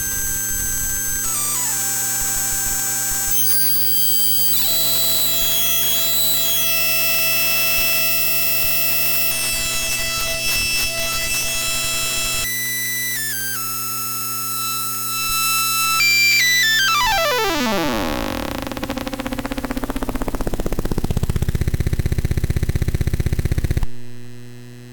Sintetizador Lo-Fi Granular Synthesizer - Smart Open Lab
El sonido básicamente es este, aunque la muestra es muy corta:
SintetizadorArduinoLoFi.mp3